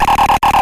电池玩具猕猴桃" 鼓高1
描述：从一个简单的电池玩具中录制的，是用一个猕猴桃代替的音调电阻！
Tag: 音乐学院-incongrue 电路弯曲 俯仰